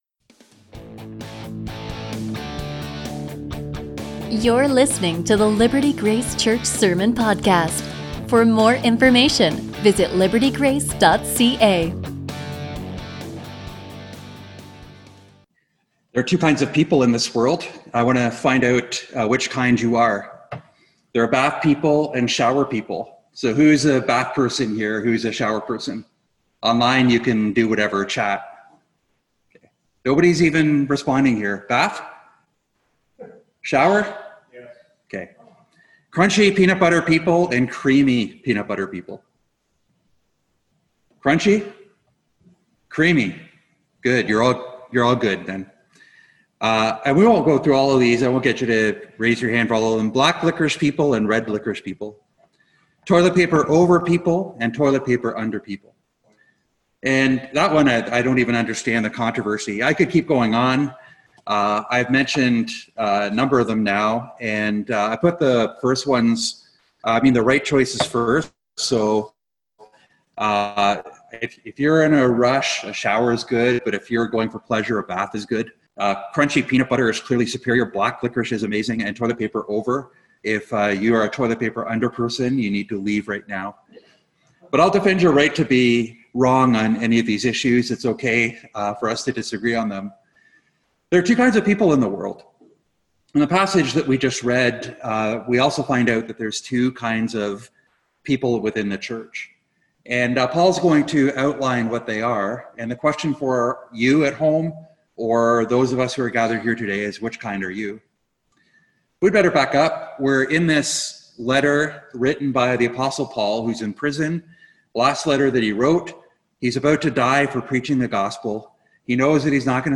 A sermon from 2 Timothy 2:20-26